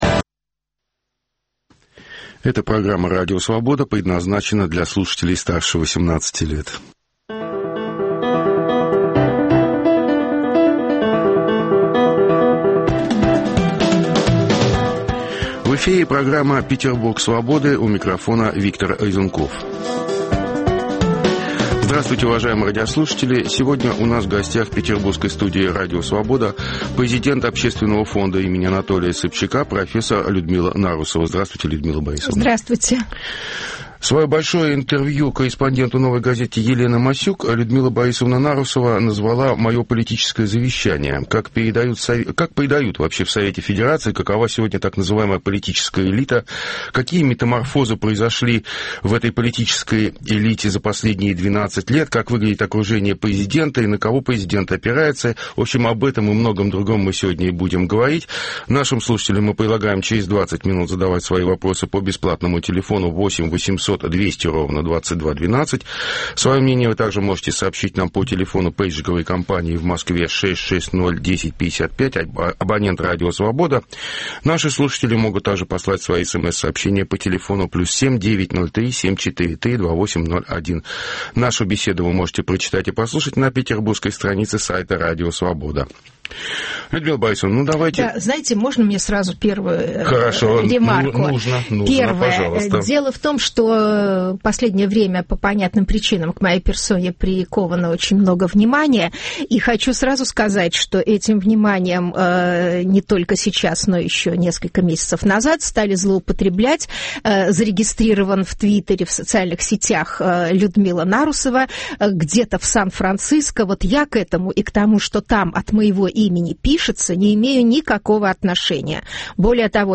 Круглый стол: Петербург Свободы
Какие метаморфозы происходят в окружении президента? Беседуем с экс-сенатором Людмилой Нарусовой.